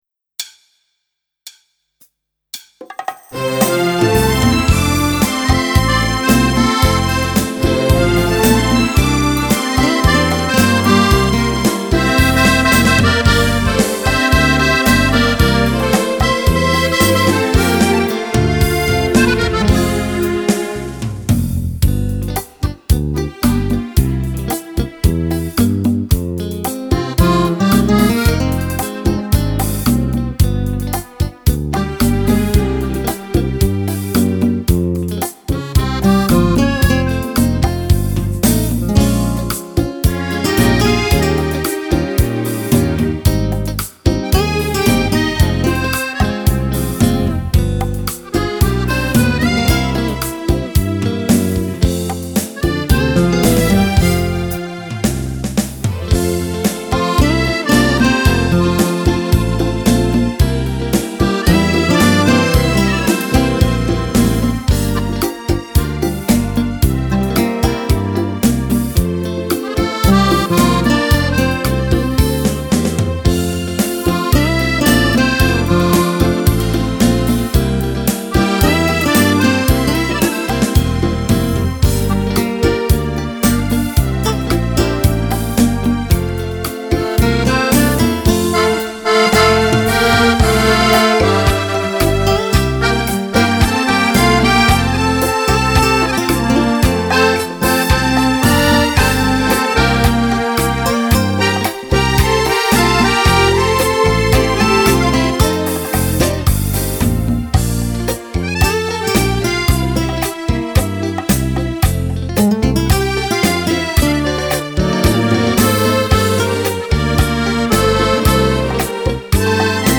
Beguine
Sax